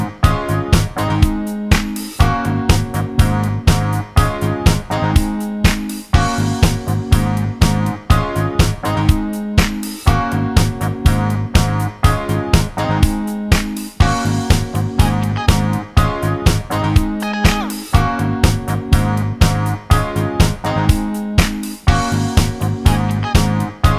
no Backing Vocals Disco 3:40 Buy £1.50